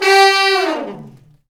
Index of /90_sSampleCDs/Roland LCDP06 Brass Sections/BRS_Section FX/BRS_Fat Falls